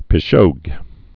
(pĭ-shōg)